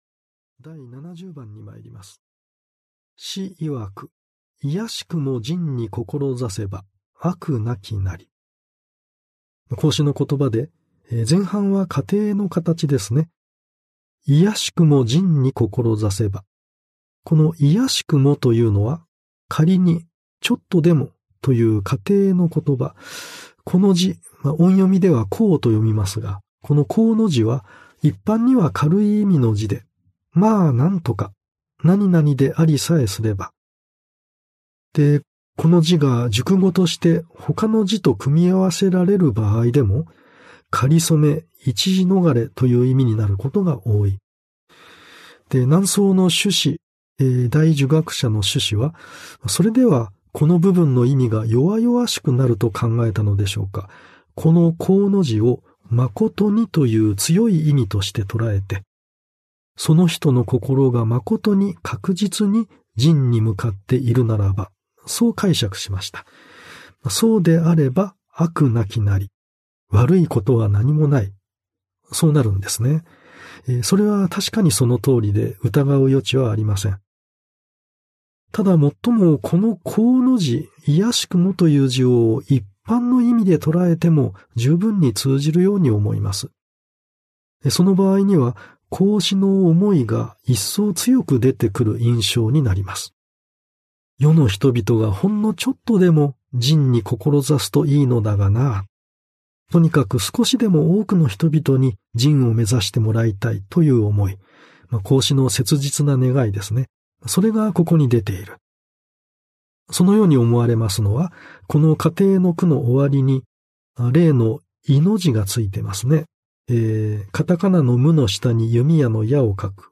[オーディオブック] 耳で聴き 心に効く 論語〈里仁第四〉